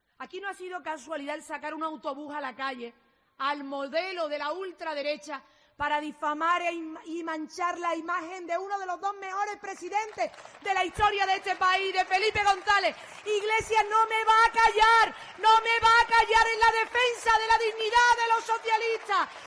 Lo ha hecho durante un acto celebrado en el Auditorio-Palacio de Congresos Mar de Vigo bajo el lema "Vigo con Susana", que ha comenzado con media hora de retraso.
Allí ha estado arropada por unas 350 personas, entre las cuales se encontraban diferentes personalidades socialistas como el alcalde de la ciudad y presidente de la FEMP, Abel Caballero, o la presidenta de la Diputación de Pontevedra, Carmela Silva.